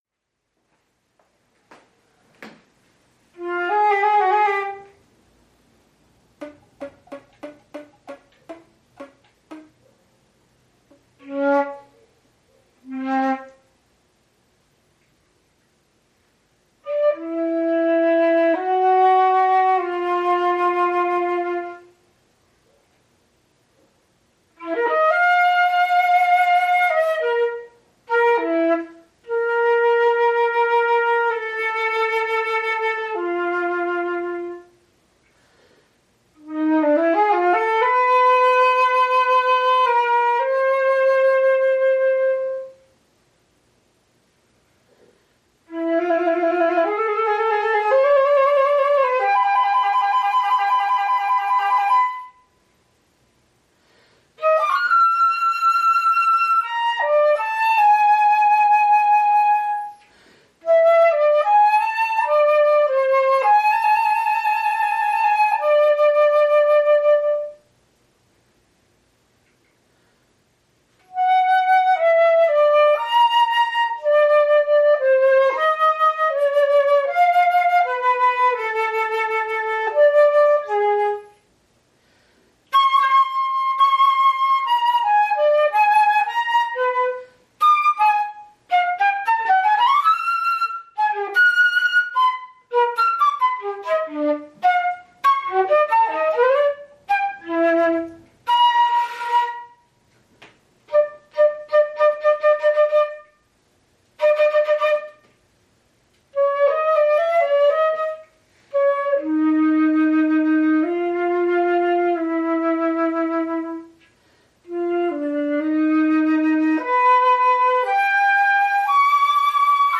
For solo flute.